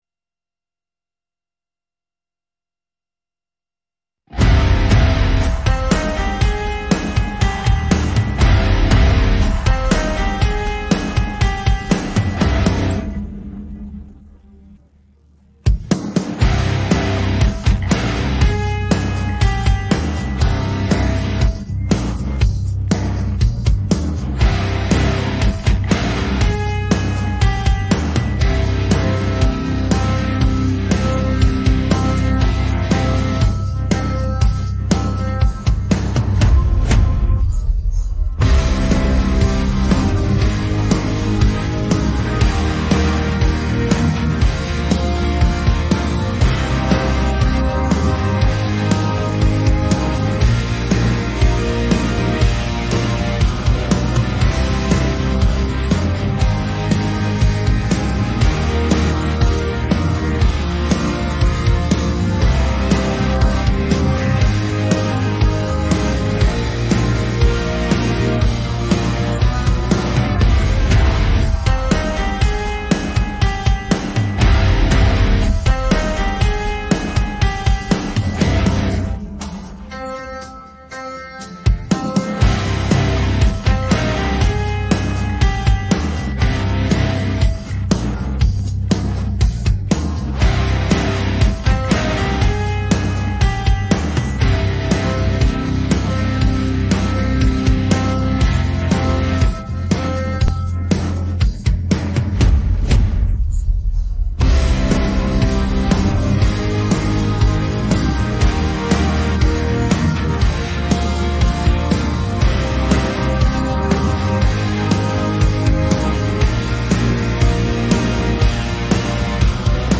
мінус караоке